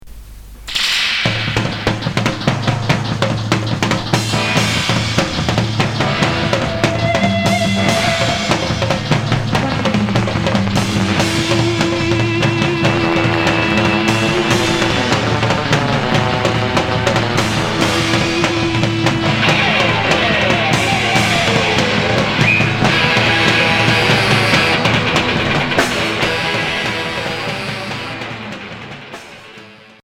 Garage punk